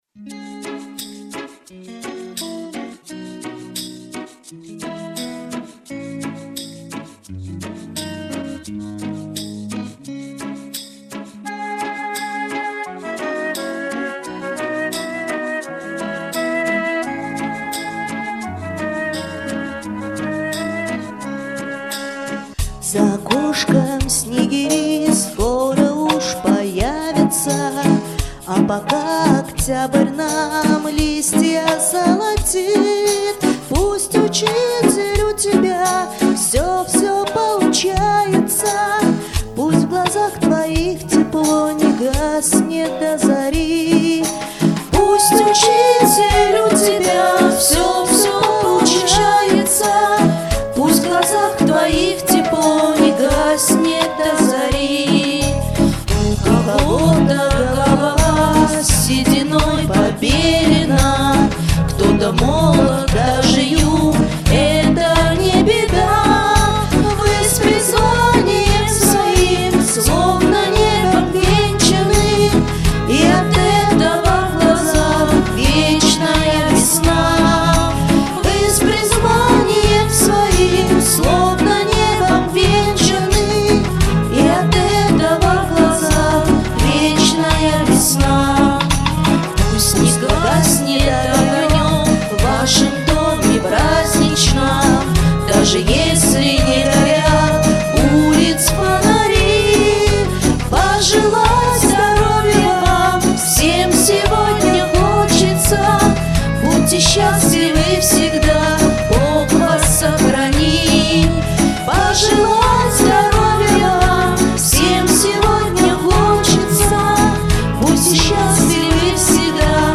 • Жанр: Детские песни
теги: день учителя, минус, пародия, переделка